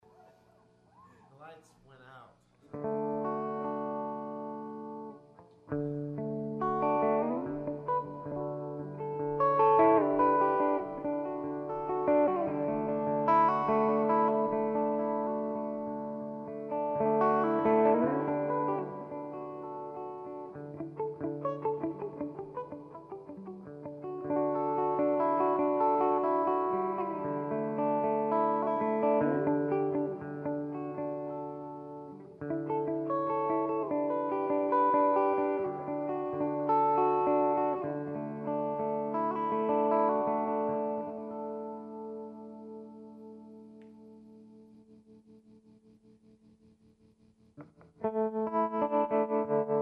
REC: Rode NT1 and a Sure sm57 into Cubase | No effects added.